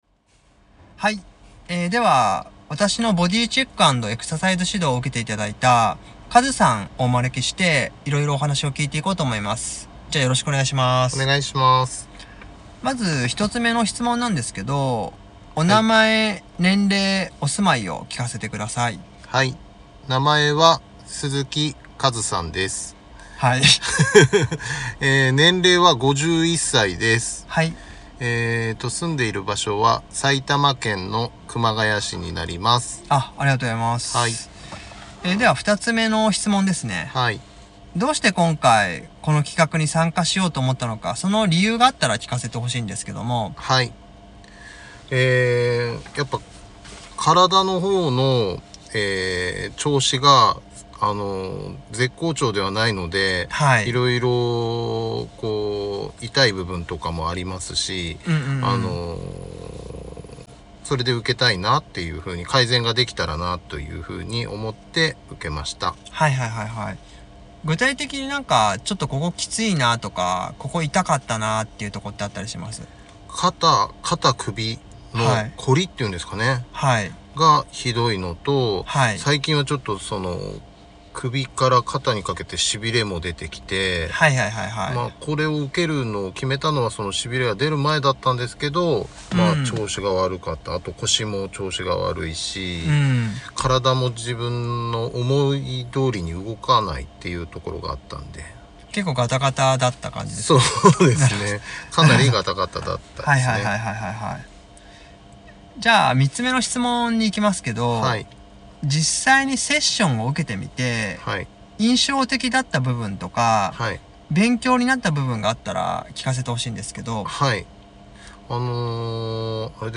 参考までに、以前私の「ボディチェック＆エクササイズ指導」に参加して頂いた方々へのインタビュー音声を一部載せておきます。